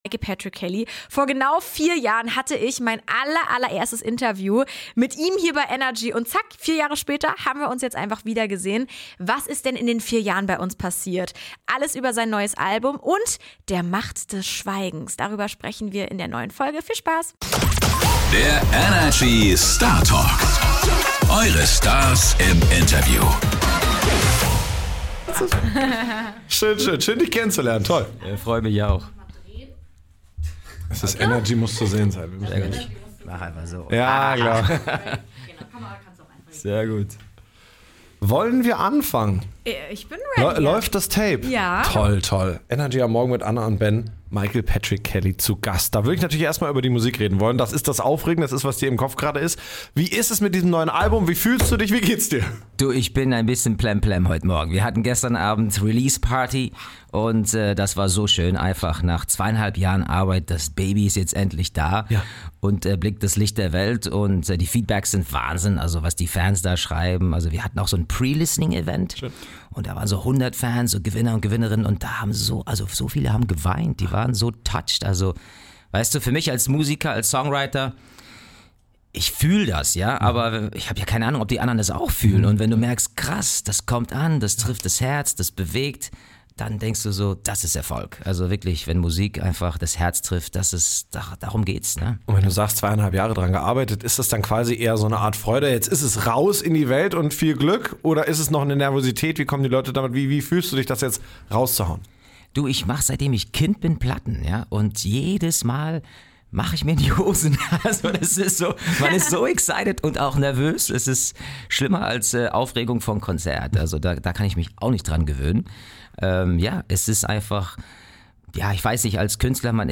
Von persönlichen Entwicklungen über sein neues Album bis hin zur Macht des Schweigens: Ein Gespräch voller Ehrlichkeit, Insights und überraschender Momente.